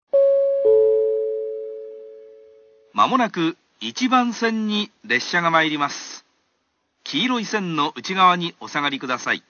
minamikoshigaya1-ap.wav